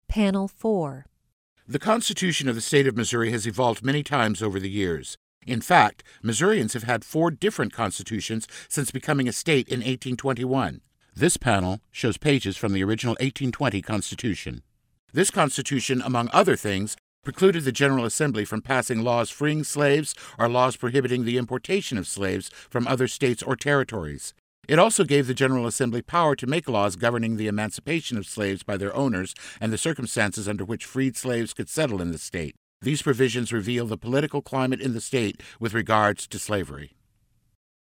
Divided Loyalties Audio Tour